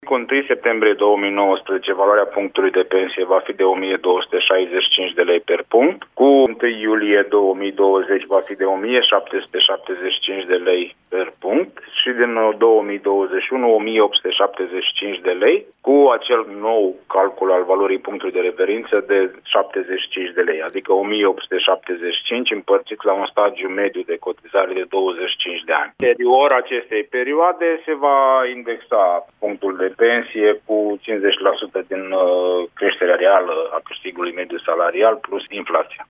Principala prevedere a legii, în forma actuală, stipulează că persoanele care au realizat cel puţin stagiul minim de cotizare de 15 ani beneficiază de pensie de asigurări sociale. Ministrul Muncii, Marius Budăi, a explicat, la Radio România Actualităţi, cum va creşte punctul de pensie: